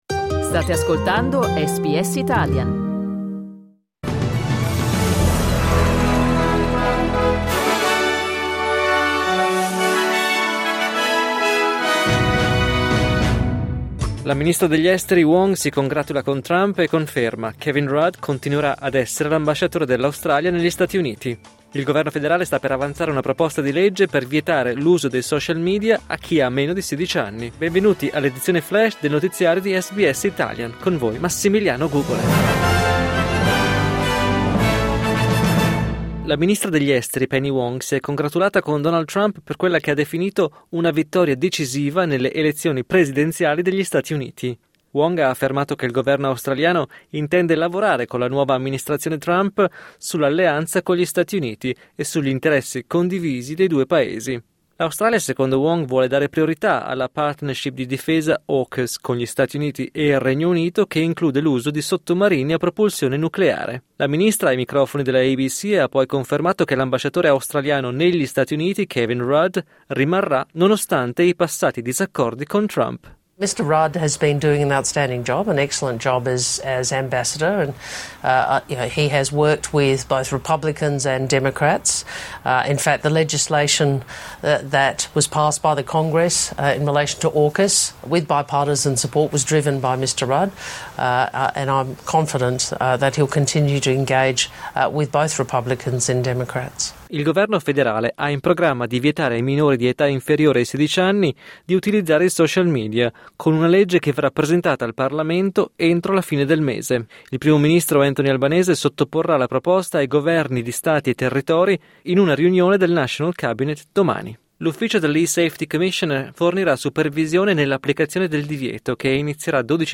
News flash giovedì 7 novembre 2024